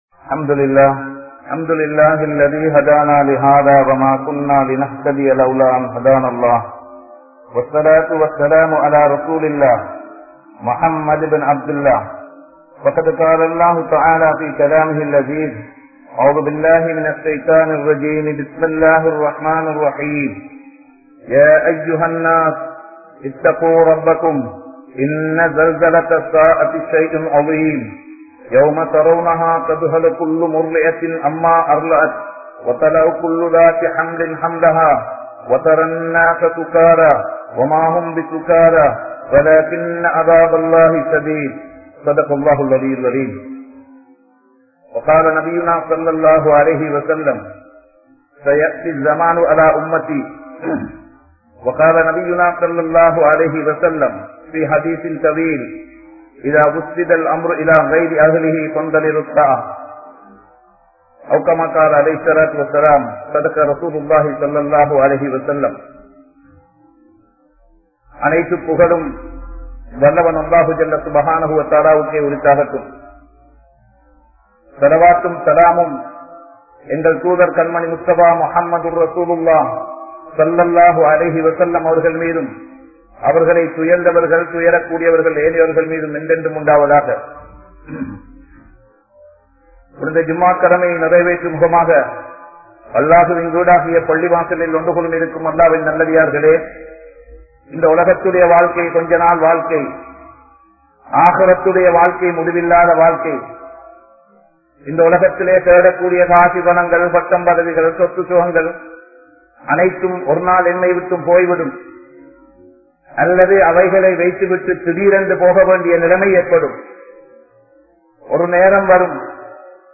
Qiyamath Naal(கியாமத் நாள்) | Audio Bayans | All Ceylon Muslim Youth Community | Addalaichenai
Grand Jumua Masjith